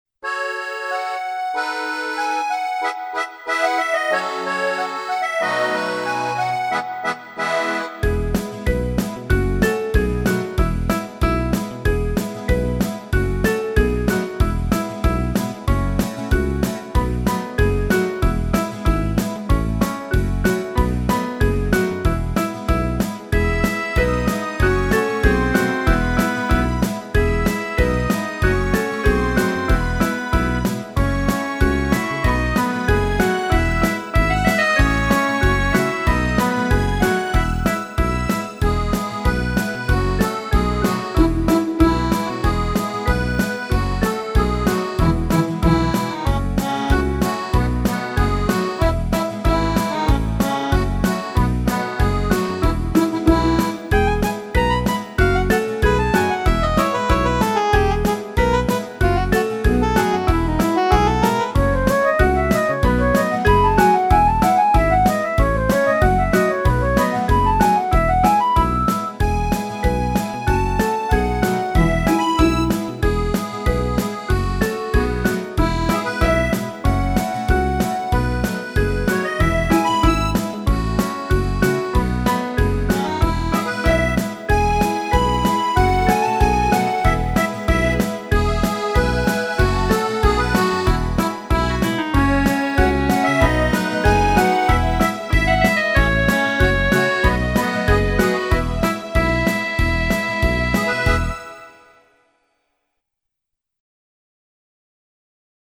Русская народная песня в обр.
минус, народный оркестр
русской народной песне